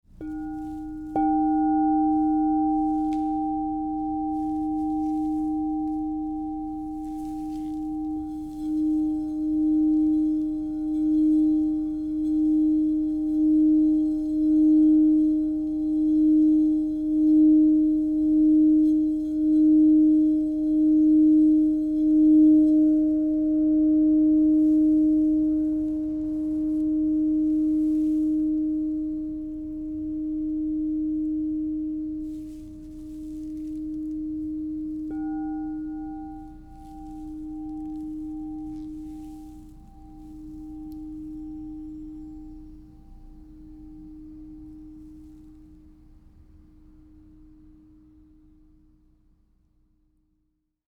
Crystal Tones® Rose Quartz 9 Inch D# Alchemy Singing Bowl
The 9-inch size delivers rich and resonant tones, making it ideal for meditation, sound therapy, and enhancing sacred spaces.
Experience this 9″ Crystal Tones® alchemy singing bowl made with Rose Quartz, Platinum w/etched Roses in the key of D# -15.
432Hz (-)